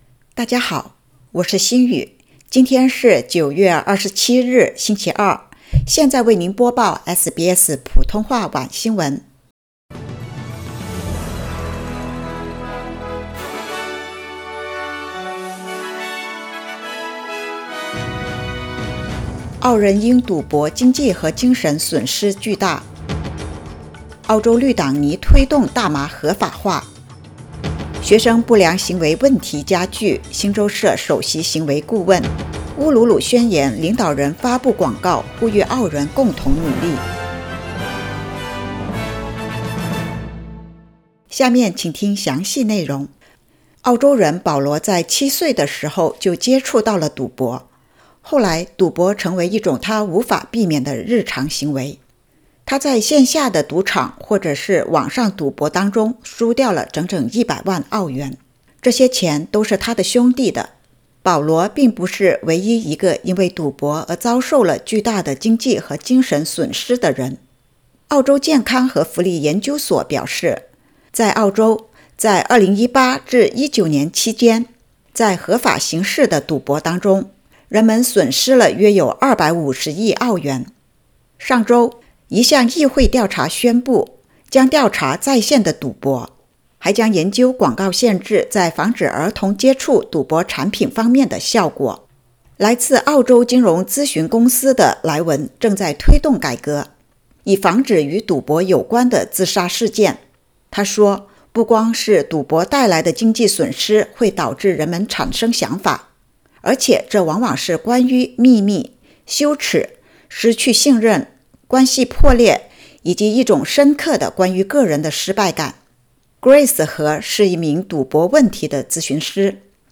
SBS晚新闻（2022年9月27日）